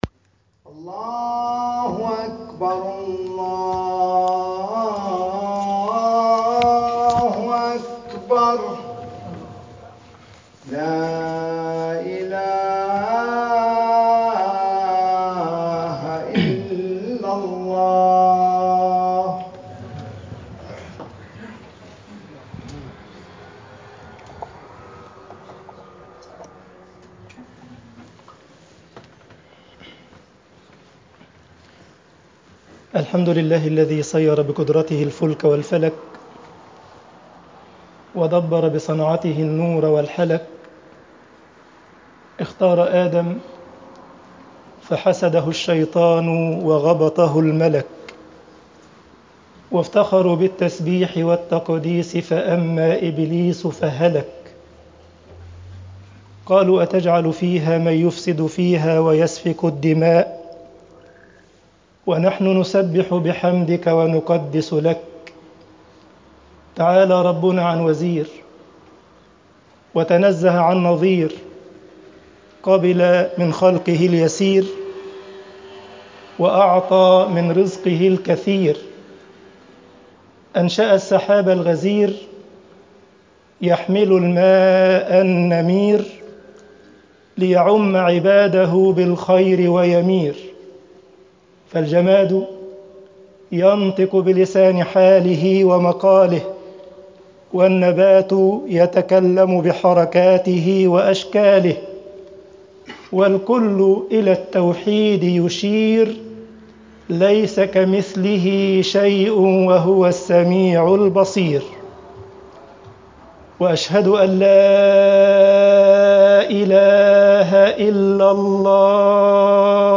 خطب الجمعة والعيد
Freitagsgebet_tahwil alqiblah mit dalail annubouah.mp3